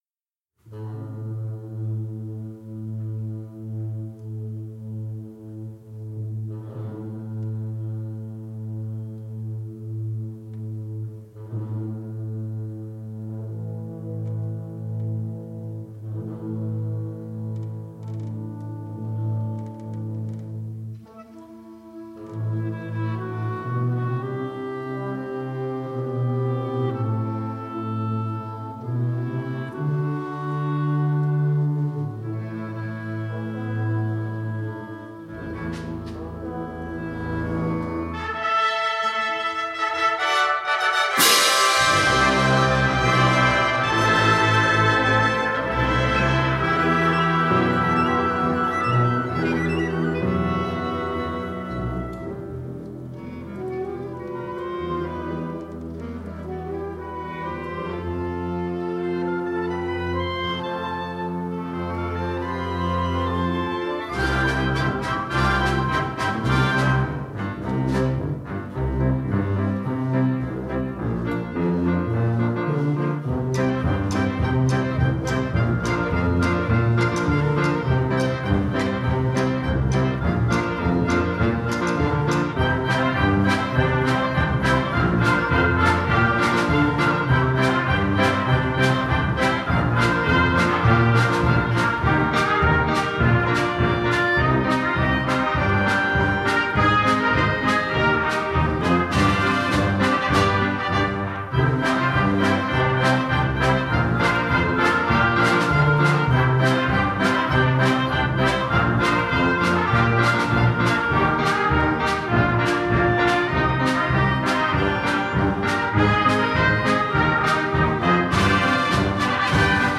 Youth Wind Ensemble of Westchester Fall 2016
Youth Wind Ensemble of Westchester Fall Concert December 16, 2016